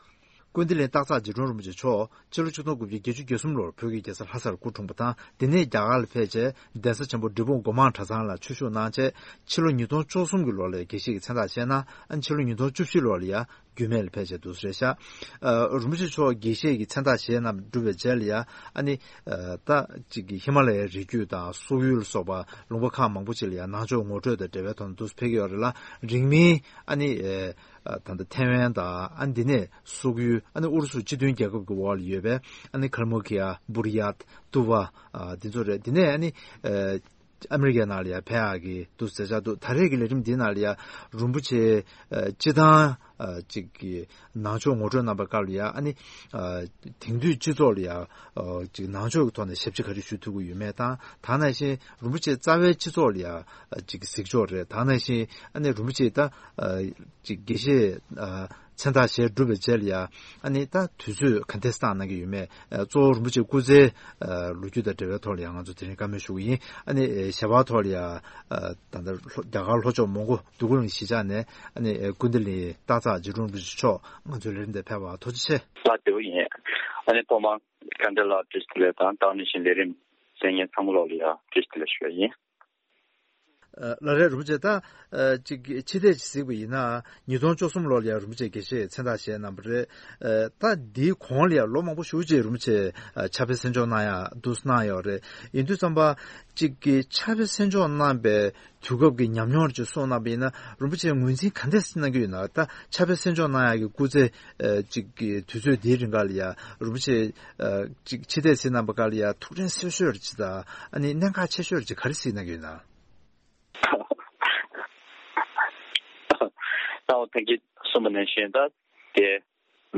གླེང་མོལ།